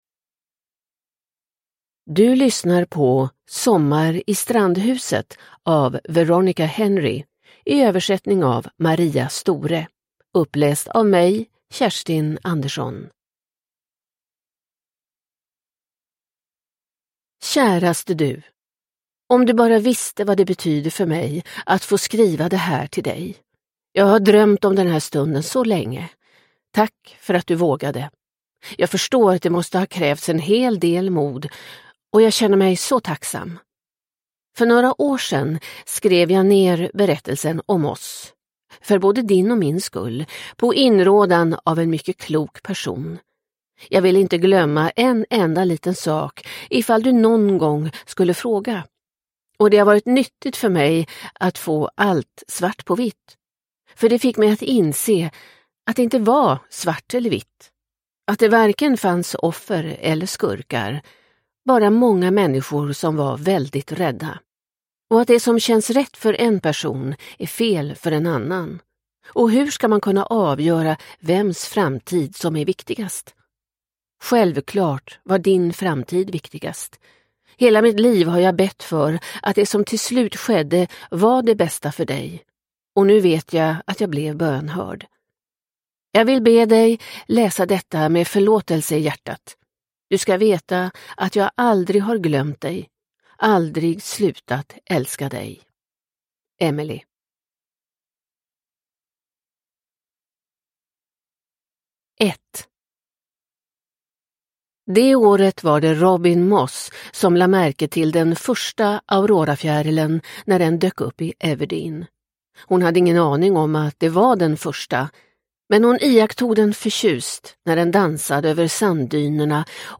Sommar i strandhuset – Ljudbok – Laddas ner